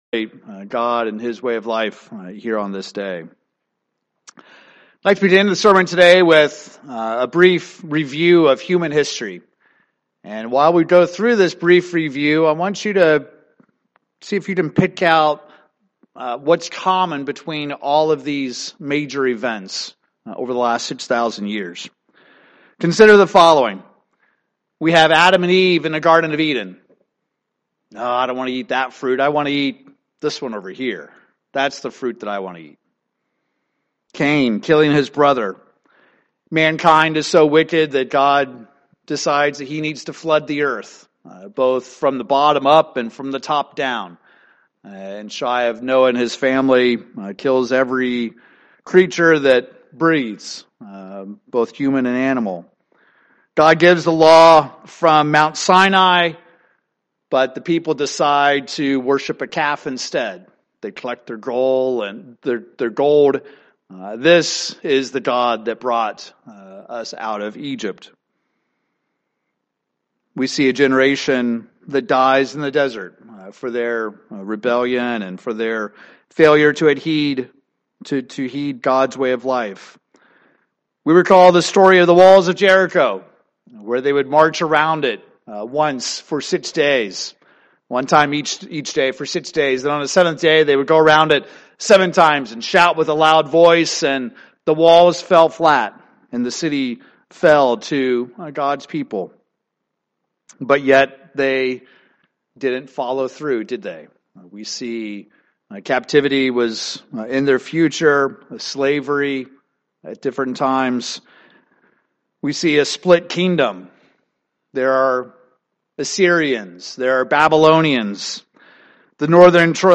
Did the Apostle Paul also lower the standard of how we are to be living today? In this sermon, we will examine this word "Grace" to see what it really means.